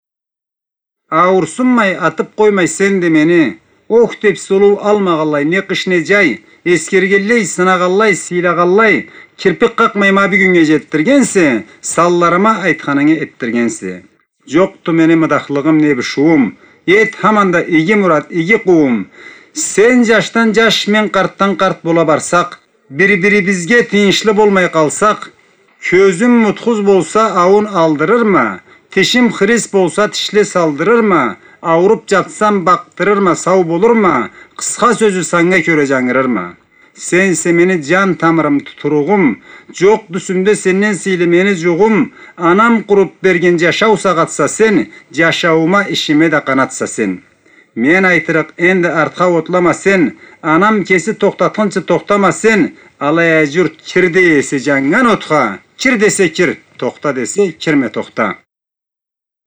назму